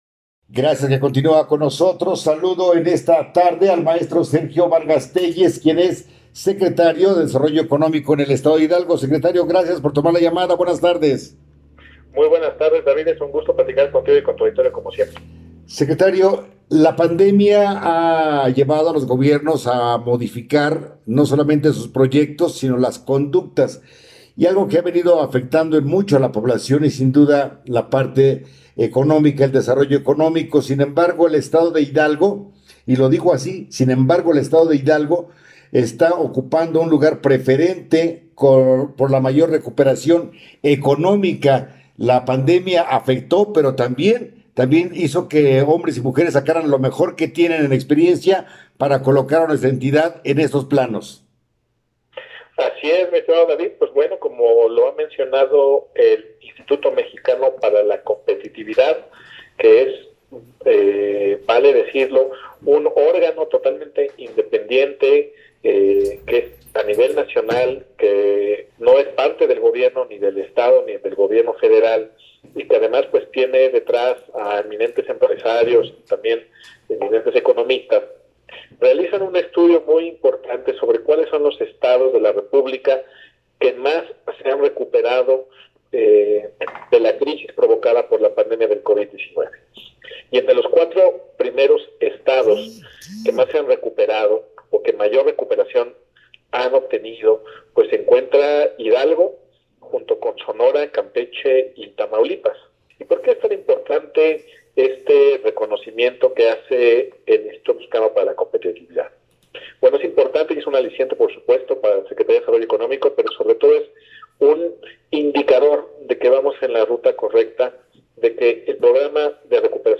Entrevista-Sergio-Vargas-Tellez.mp3